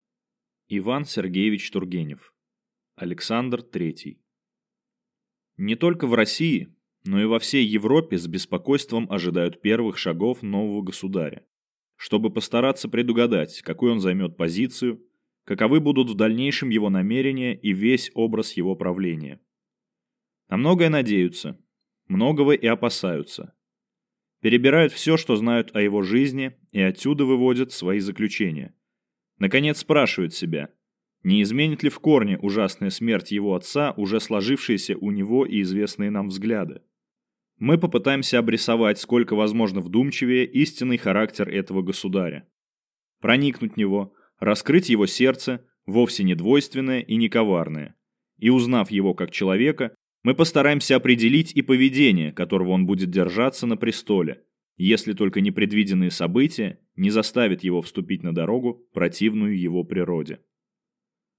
Аудиокнига Александр III | Библиотека аудиокниг